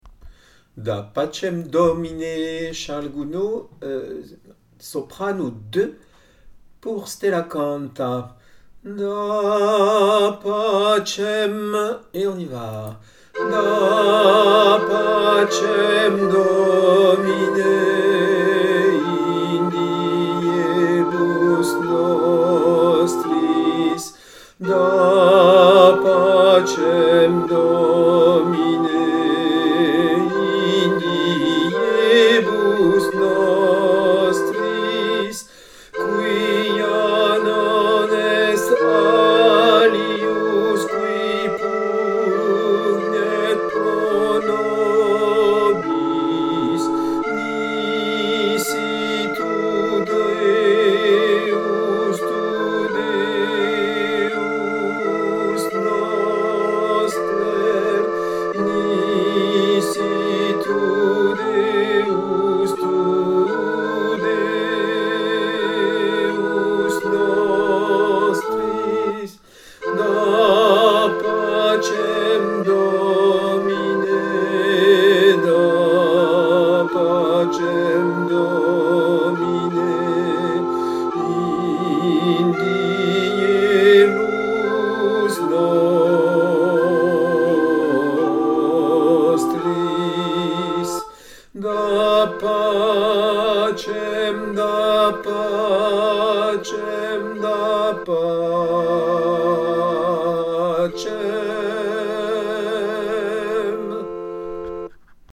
Fichiers chantés par le Chef
da_pacem_domine_sop_2.mp3